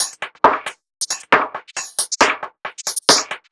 Index of /musicradar/uk-garage-samples/136bpm Lines n Loops/Beats
GA_BeatRingB136-07.wav